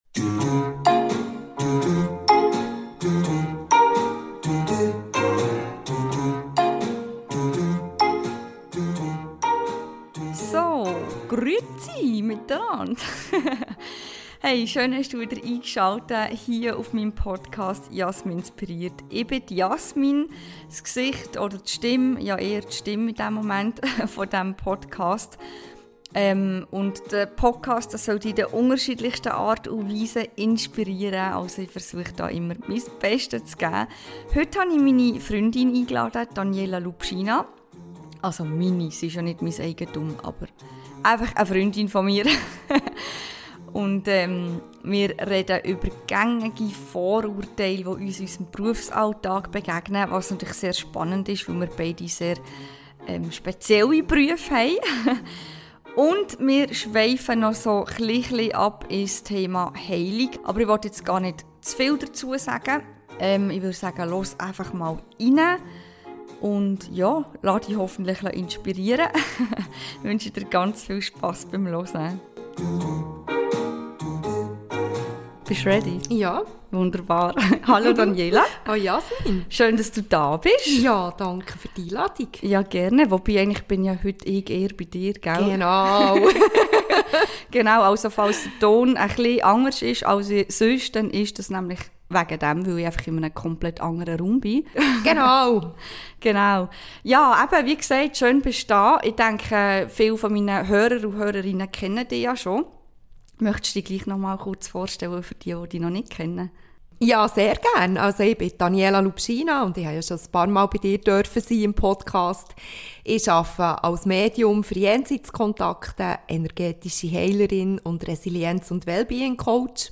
Sprache: Schweizerdeutsch